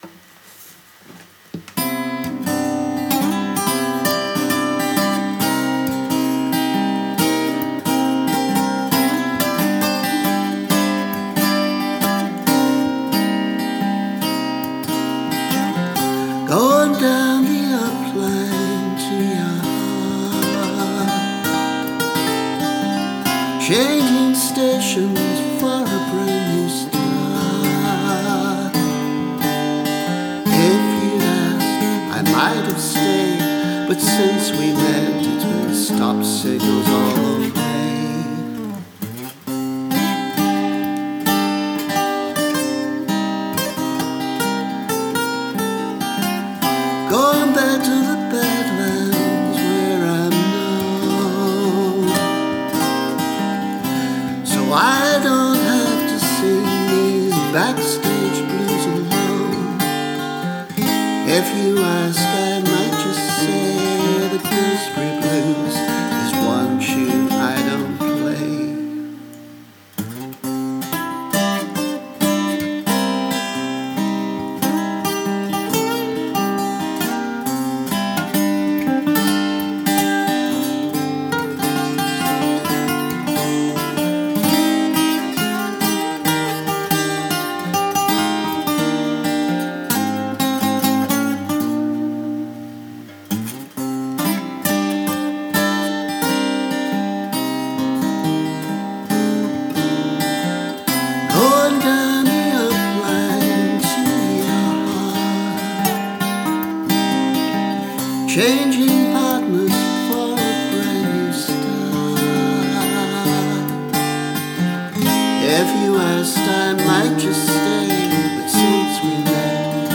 A slightly weird little song.
Barebones arrangement: vocal and rough rhythm track (because I discovered I didn’t like the original chords as much 30 years on).